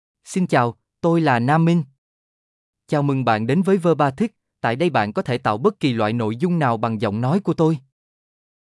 NamMinh — Male Vietnamese (Vietnam) AI Voice | TTS, Voice Cloning & Video | Verbatik AI
NamMinh is a male AI voice for Vietnamese (Vietnam).
Voice sample
Male
NamMinh delivers clear pronunciation with authentic Vietnam Vietnamese intonation, making your content sound professionally produced.